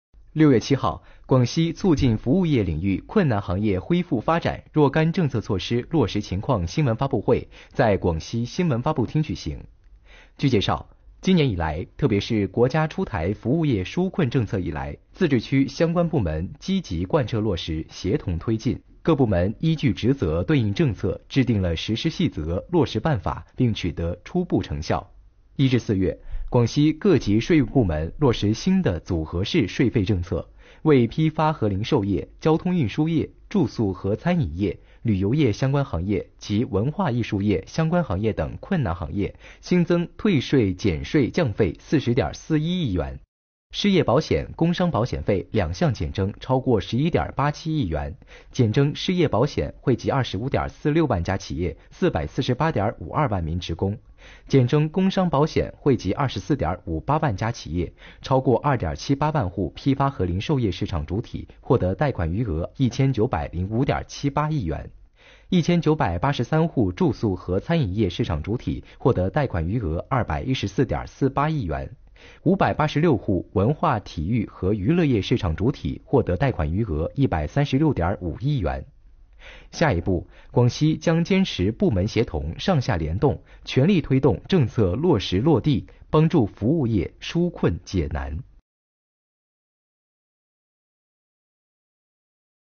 6月7日上午，广西壮族自治区人民政府新闻办公室举行广西促进服务业领域困难行业恢复发展若干政策措施落实情况新闻发布会。据不完全统计，截至5月31日，全区共缓缴三项社会保险费1.89亿元，惠及1438家企业。